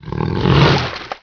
ogrefish_idle.wav